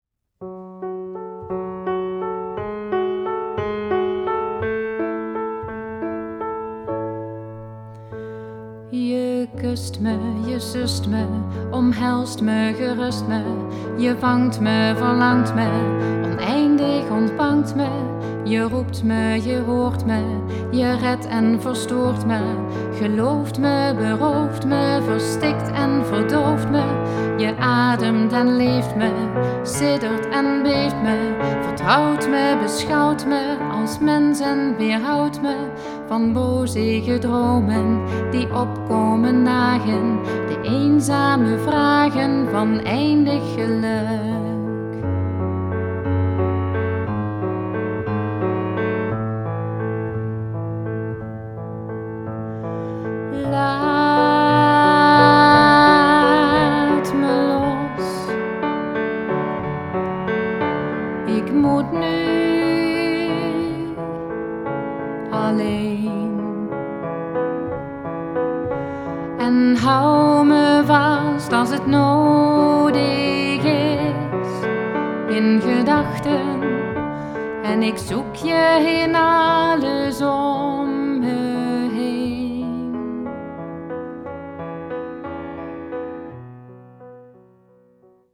Hieronder vindt u een aantal liedjes die ik regelmatig zing.